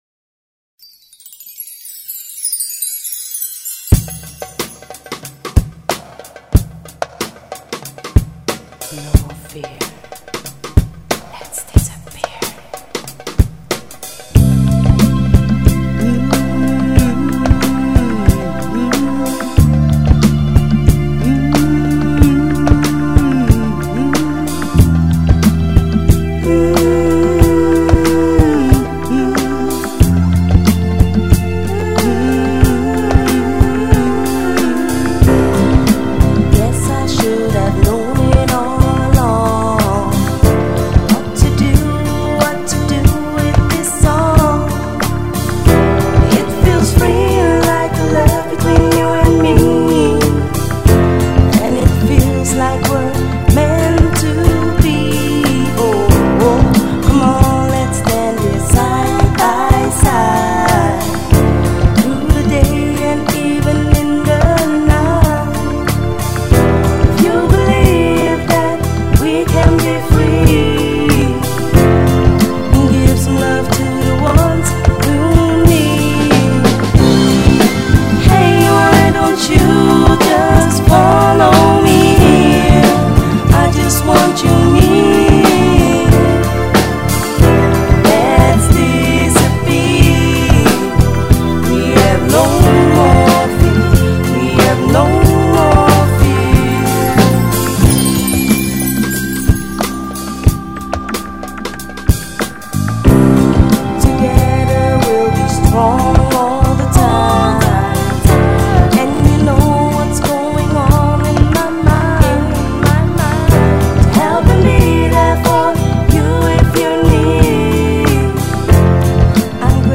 The recordings were good and my voice was smooth.
Her Style can be described as R&B/Pop/ Soul